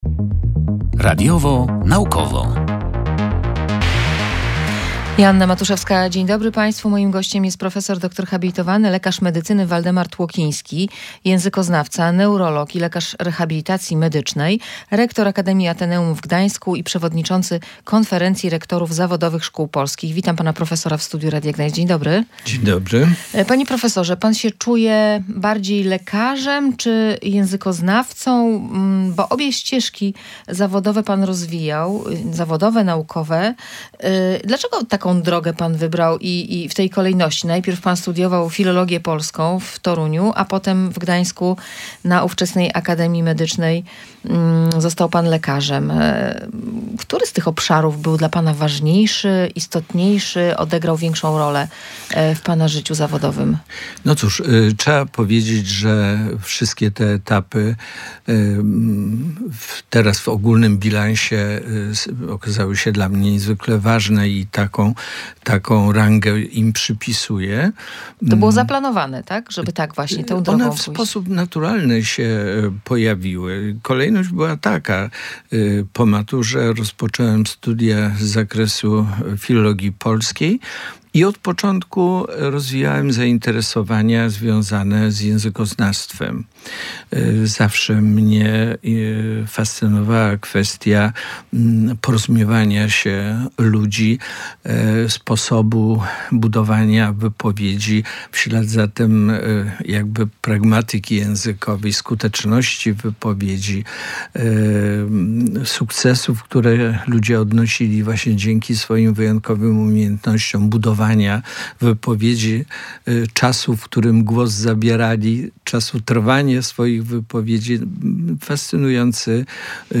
Między innymi o tym rozmawialiśmy w audycji „Radiowo-Naukowo”.